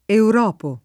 [ eur 0 po ]